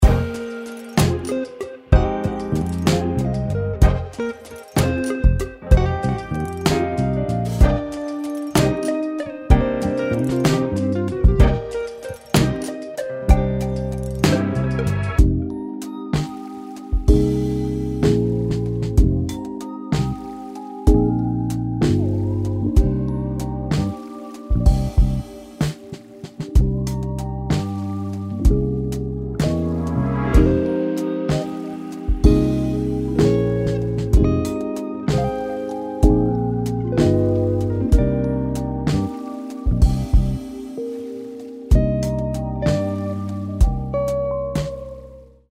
This meticulously crafted loop pack captures the essence of laid-back, relaxed Sundays, blending smooth R&B influences with the cozy aesthetic of lo-fi music. Ideal for producers and musicians aiming to infuse their tracks with warmth, soul, and a touch of nostalgia, this collection features a rich array of live instrumentation.
13 Drum Loops: Soft, groovy drum patterns that range from simple, steady beats to more intricate rhythms.
8 Delay Synth Loops: Warm, echoing synth lines that add a layer of dreamy ambiance to your productions.
3 Flute Loops: Gentle, melodic flute riffs that bring a soothing, natural touch to your music.
23 Guitar Loops: Soulful guitar riffs, with a mix of fingerpicking and strumming styles.
7 Piano Loops: Evocative piano melodies that range from soft, jazzy chords to intricate runs.
6 Strings Loops: Smooth, lush string arrangements that enhance the emotional depth of your tracks.
Live Instrumentation: Every loop in this collection is performed by skilled musicians, ensuring an authentic and expressive sound that only live instruments can provide.
Sunday-Lo-Fi-Grooves-Vol-1.mp3